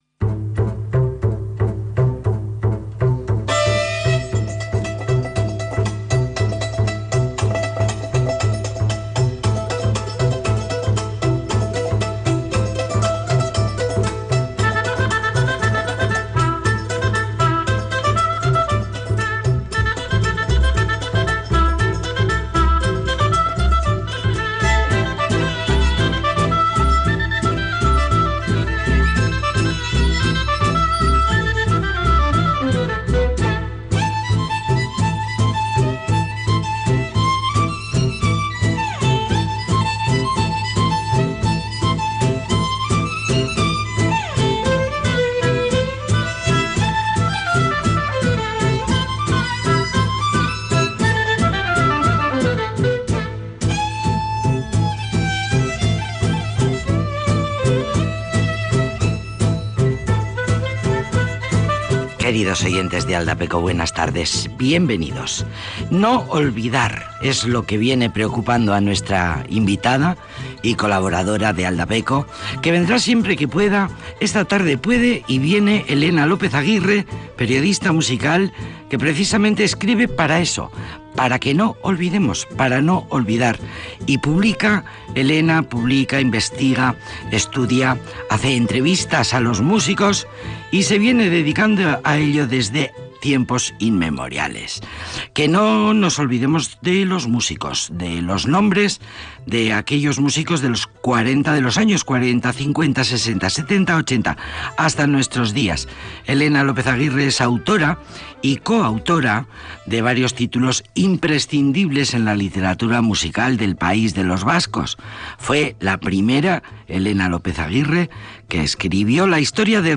Múisca y entrevistas para la sobremesa